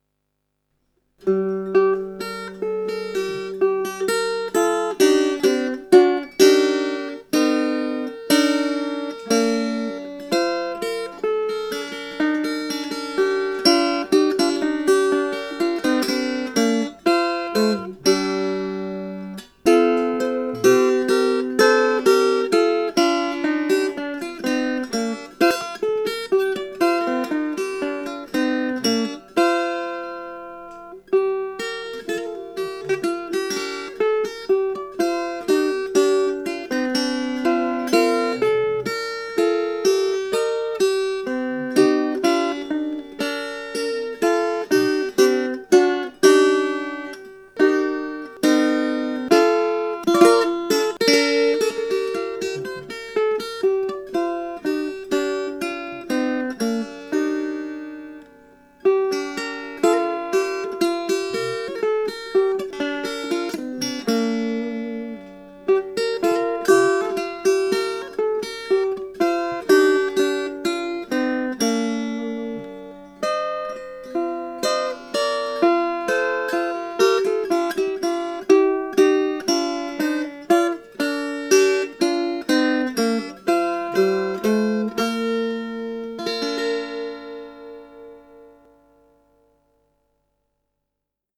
Two improvisations…
piccolo2.mp3